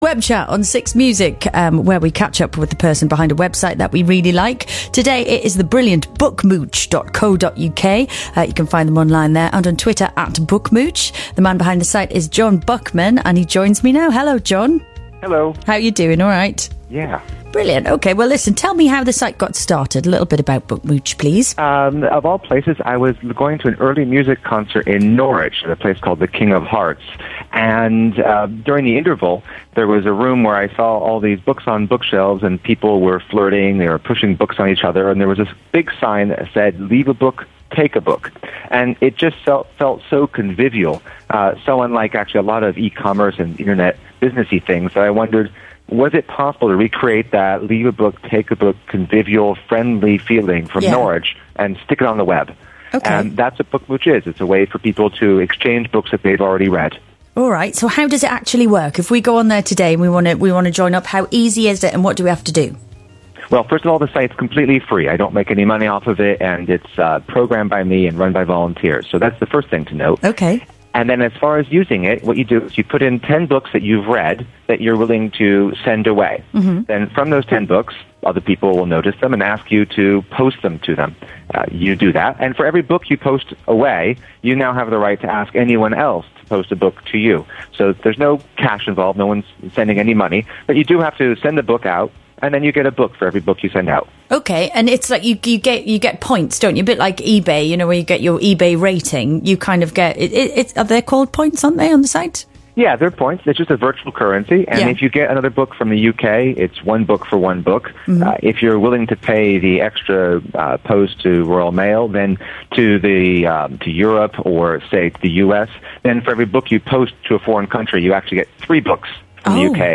BBC6 radio interview
It only lasted 5 minutes, and other than my "uhming" a bit too much, I think it turned out ok.
Not many umms noticed- I thought you did a great job with a fast style of interviewing which is not the easiest thing to respond to.
bookmooch-bbc6-interview.mp3